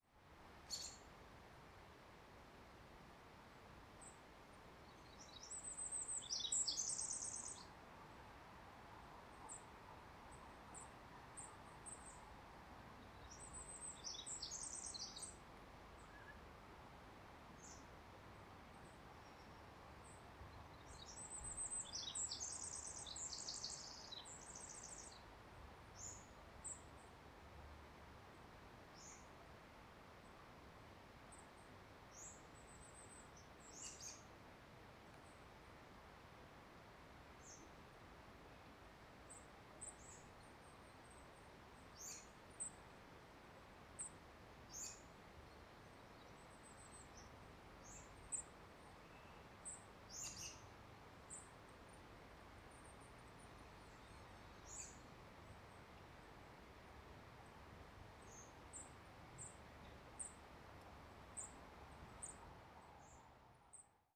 Recordings from the trail through the beautiful old growth forest at Fillongley Provincial Park on Denman Island with the bird- and creek-sounds in early spring 2022.
2. Bird sounds – Robin, Wren, Kinglet, and Chickadee